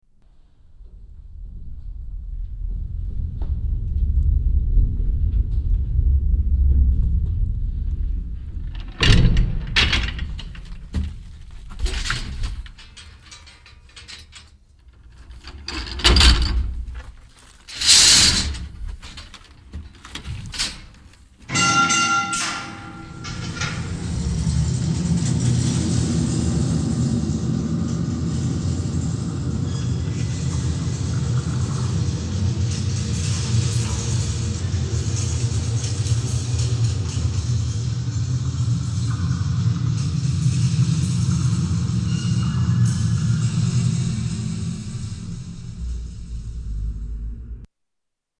Elevator swirling down the mineshaft
Tags: Travel Sounds of Czech Republic Czech Republic Prague Vacation